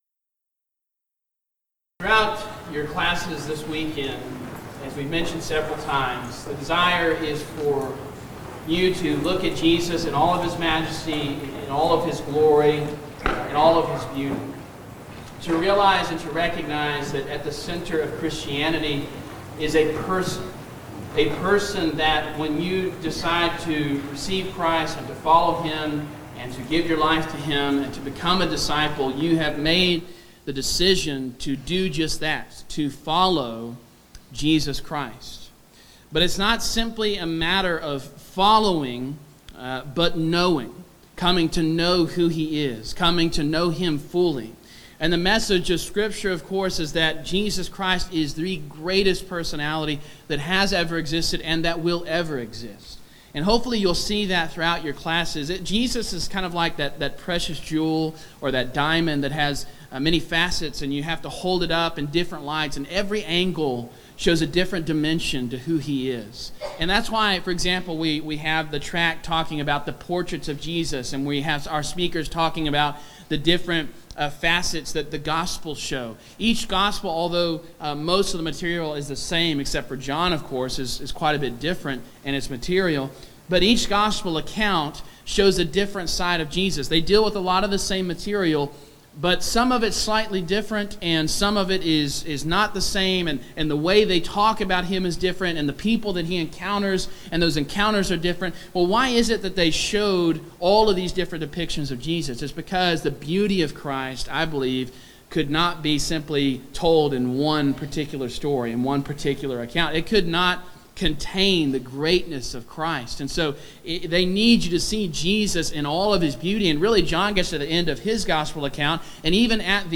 Title: Morning Devotional
Event: Discipleship U 2016 Theme/Title: Encountering Christ: Experience the Majesty of Jesus